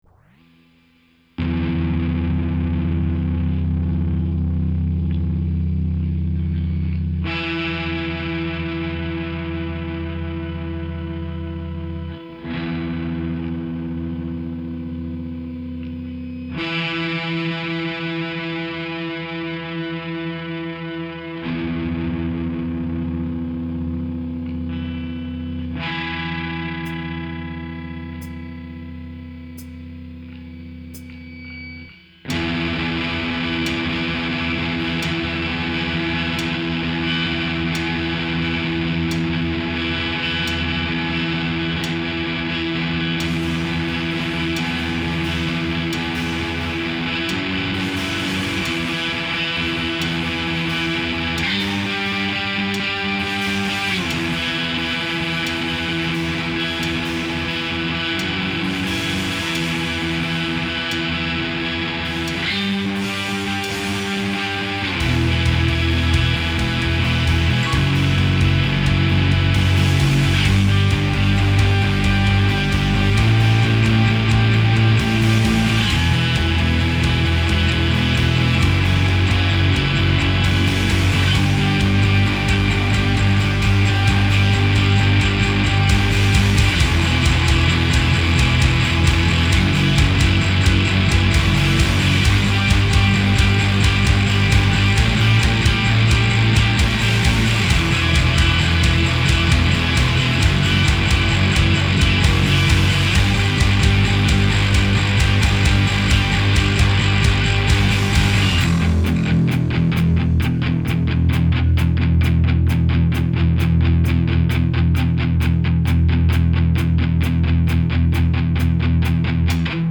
industrial-strength power dirge
mechanized punk fugue
Bass
Drum Machine – Percussion
Guitar, Vocals